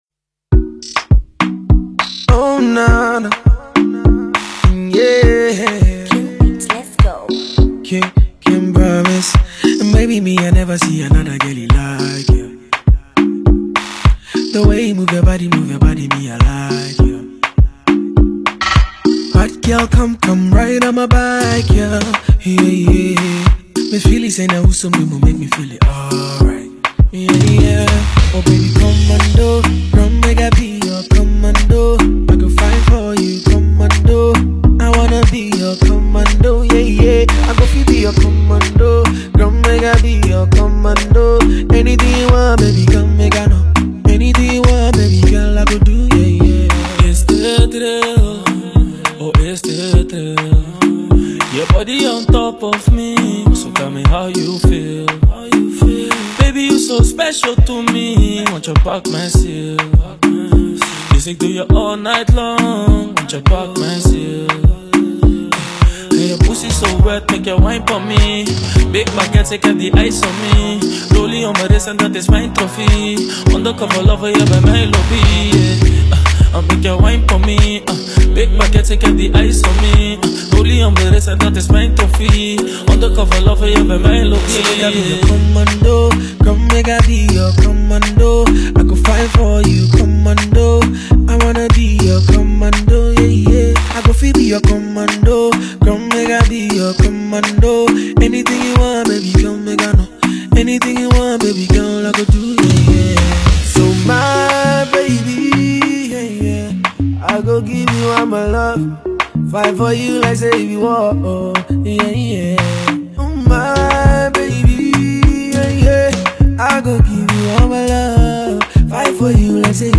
highlife, hip life